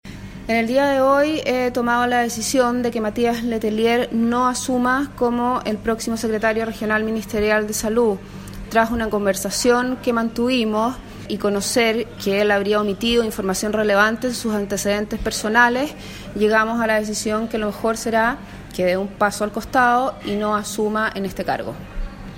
AUDIO INTENDENTA
intendenta-lucia-pinto-declaracion-publica.mp3